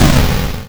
ihob/Assets/Extensions/explosionsoundslite/sounds/bakuhatu29.wav at master
bakuhatu29.wav